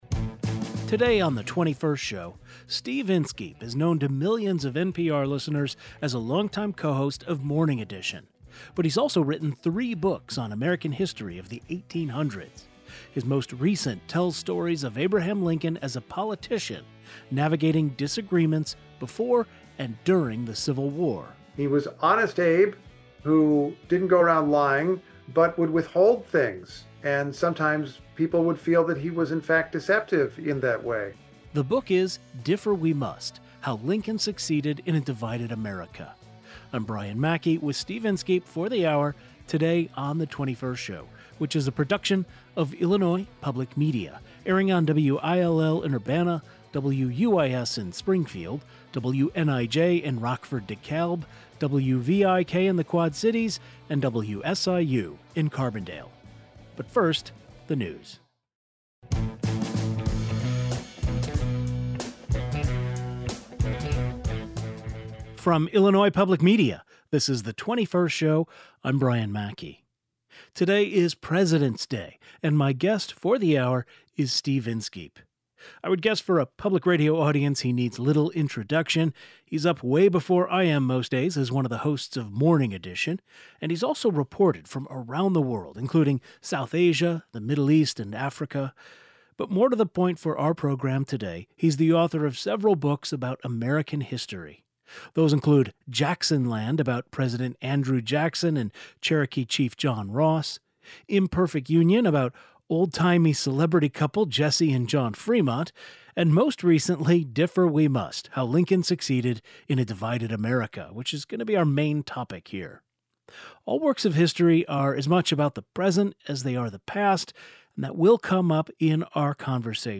Today's show included a rebroadcast of the following "best of" segment first aired on January 16, 2024: NPR’s Steve Inskeep talks about Abraham Lincoln’s political savviness in newest book.